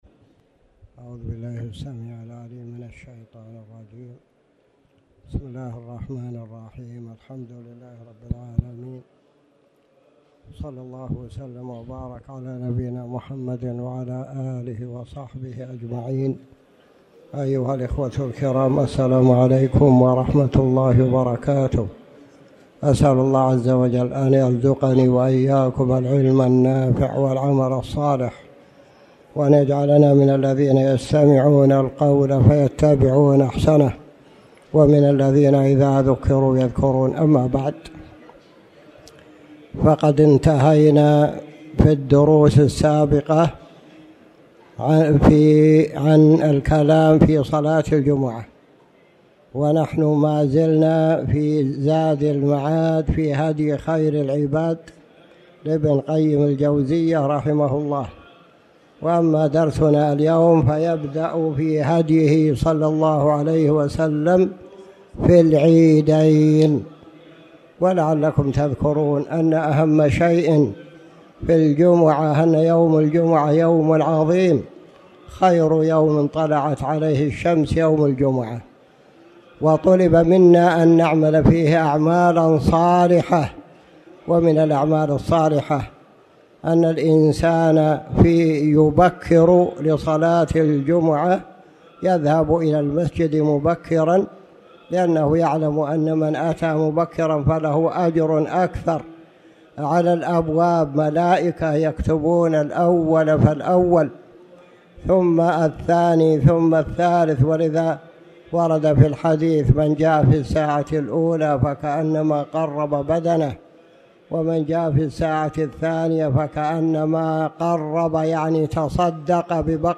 تاريخ النشر ٢٨ ذو الحجة ١٤٣٩ هـ المكان: المسجد الحرام الشيخ